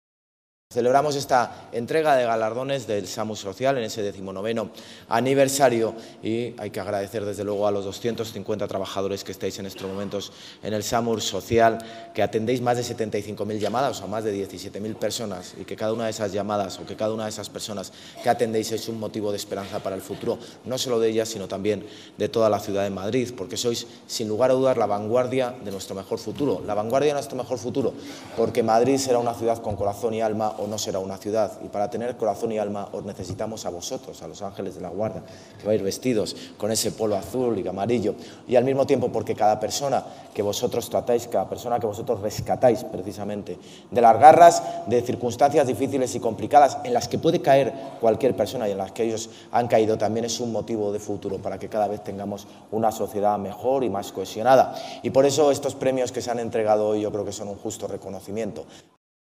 Durante el acto de entrega de los premios en conmemoración del XIX aniversario de este servicio municipal especializado en las emergencias sociales
Nueva ventana:José Luis Martínez-Almeida, alcalde de Madrid en funciones